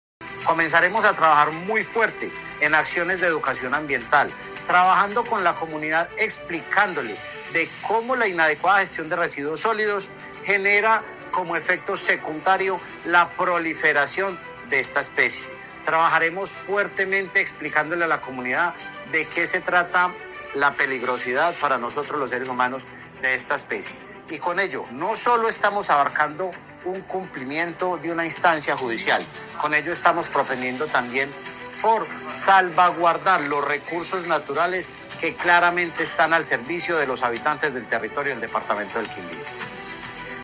AUDIO JUAN ESTEBAN CORTÉS OROZCO, DIRECTOR GENERAL (E) DE LA CRQ.